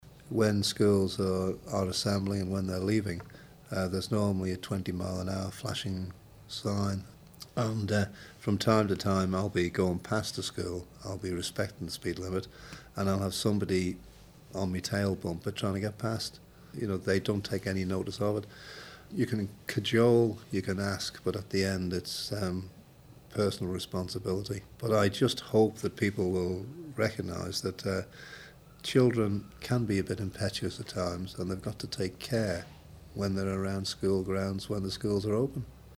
Education and Children Minister Eddie Teare told 3FM news that it's frustrating when people don't stick to the 20mile per hour speed limits.